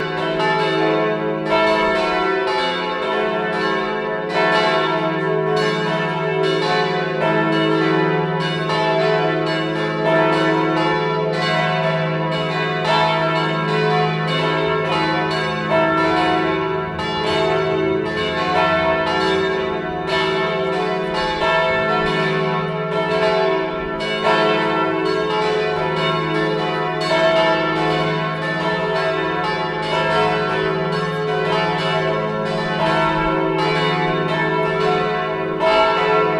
202008_Glockenläuten